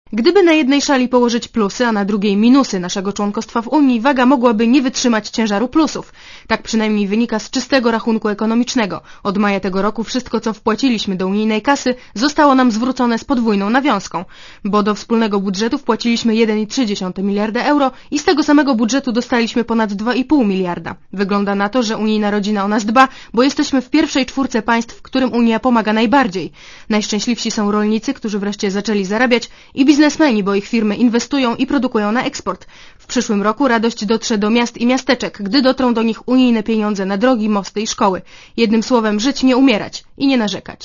Komentarz reportera Radia Zet